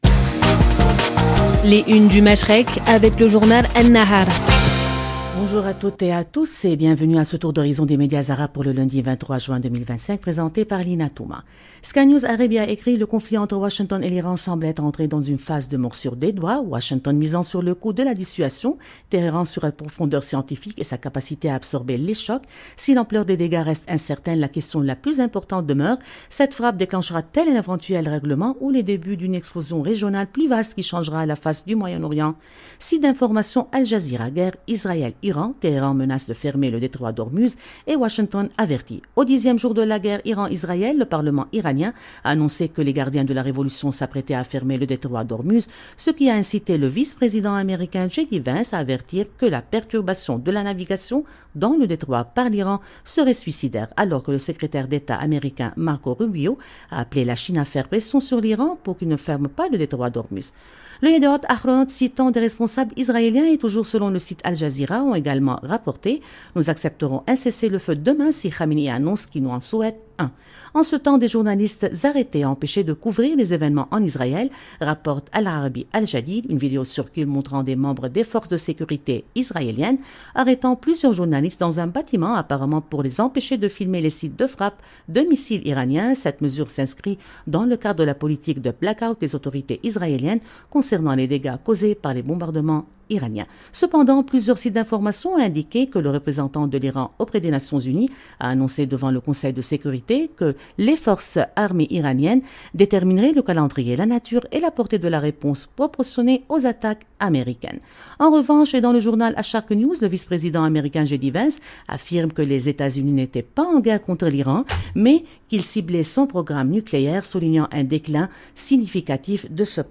Chaque matin, Radio Orient vous propose, en partenariat avec le journal libanais An-Nahar, une revue de presse complète des grands titres du Moyen-Orient et du Golfe.